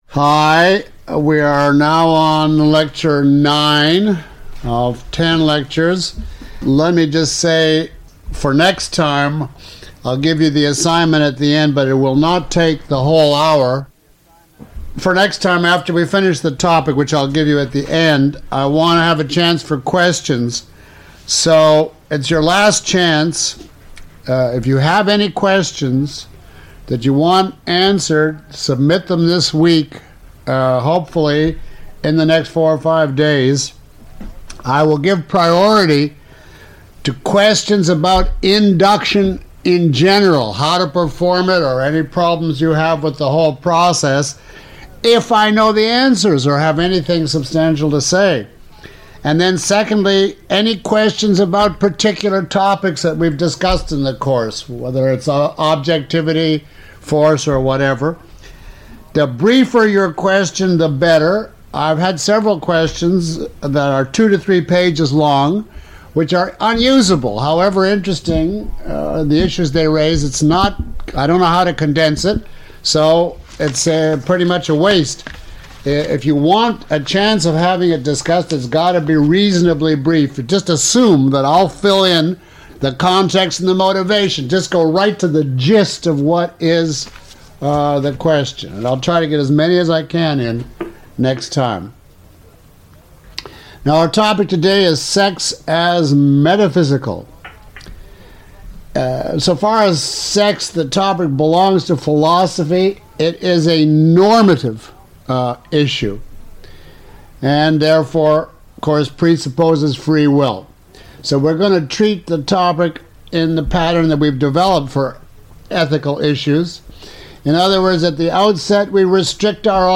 [Lecture Nine] Objectivism Through Induction by Dr. Leonard Peikoff